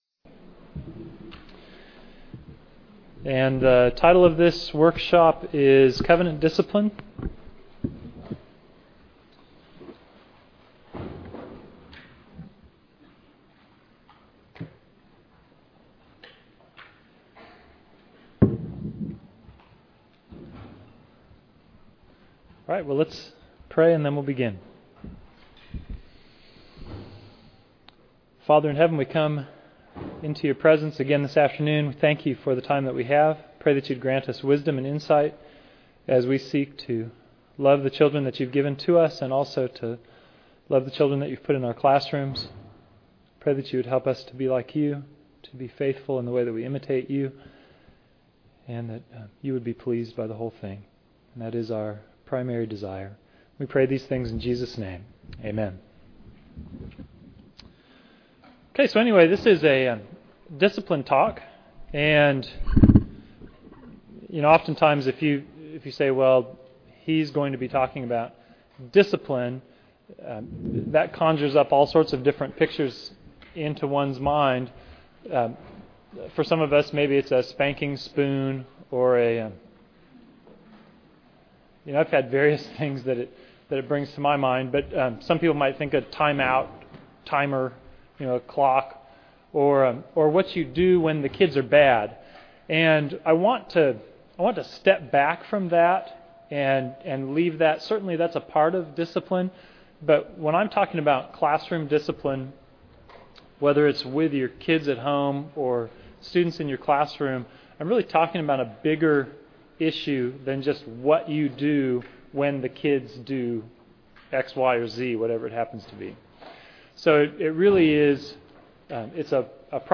2009 Workshop Talk | 1:03:08 | All Grade Levels, Virtue, Character, Discipline
Additional Materials The Association of Classical & Christian Schools presents Repairing the Ruins, the ACCS annual conference, copyright ACCS.